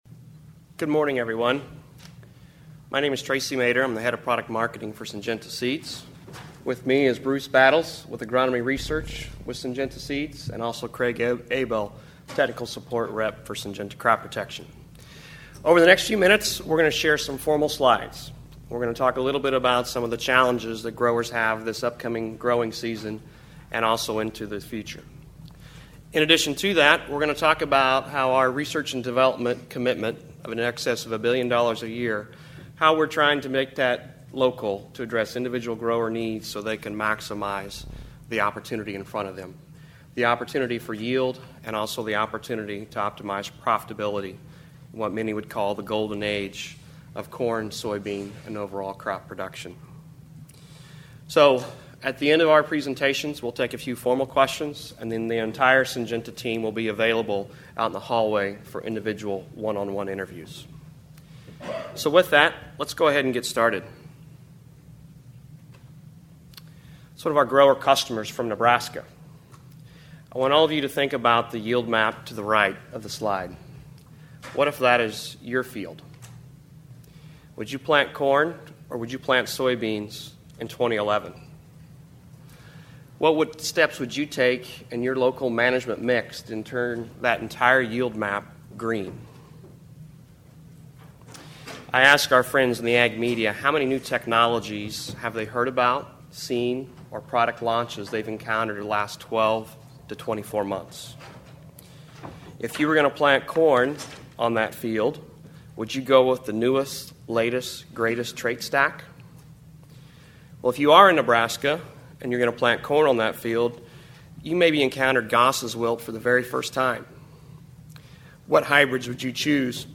Syngenta held a press conference at the recent Commodity Classic to discuss challenges growers have in the upcoming season and the Syngenta commitment to make their R&D investment “local” to the grower.
I was able to record the press conference for your listening pleasure but didn’t have a chance to talk one on one at that time.